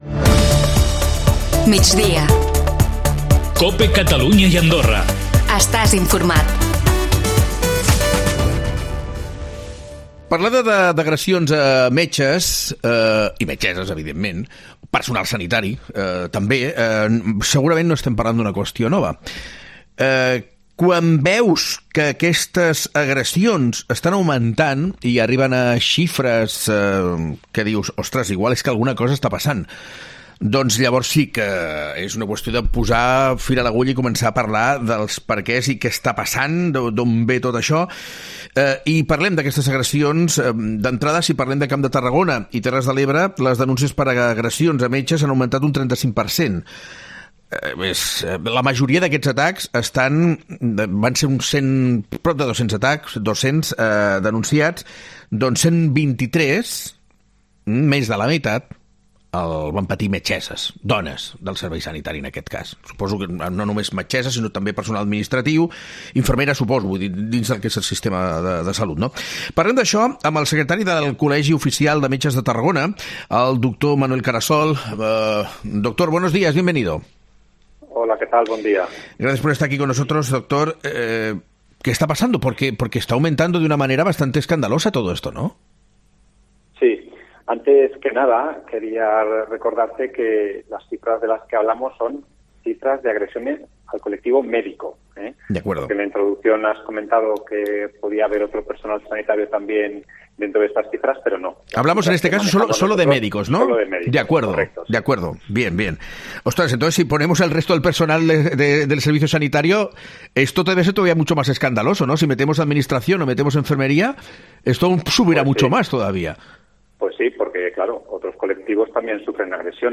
ESCUCHA LA ENTREVISTA SOBRE EL TEMA